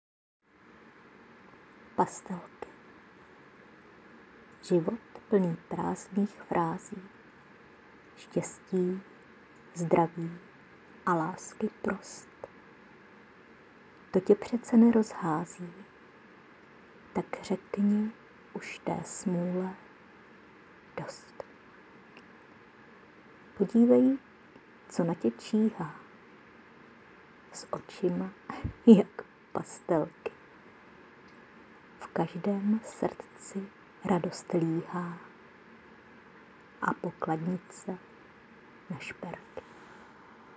pěkné poselství Tvá myšlenka zvěstuje... a máš příjemný hlas :-)... děkuji za příjemnou chvíli u Tebe